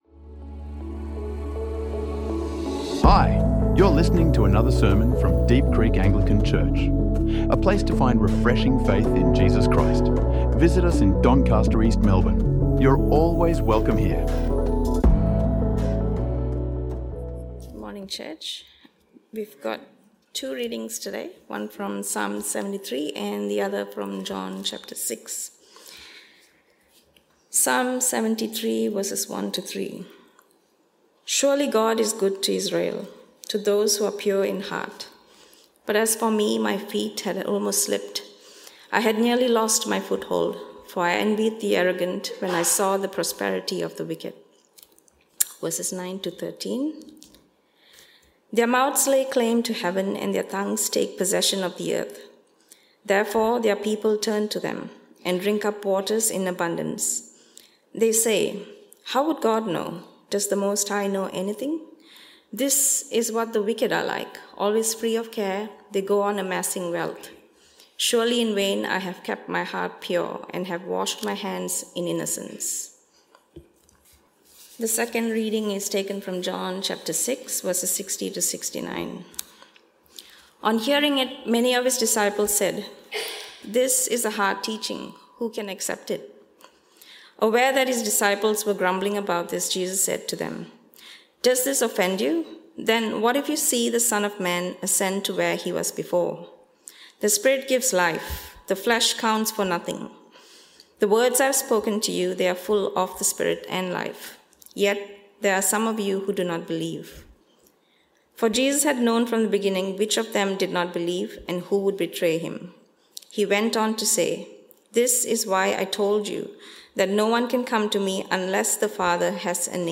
Jesus is Good For The Deconstructing | Sermons | Deep Creek Anglican Church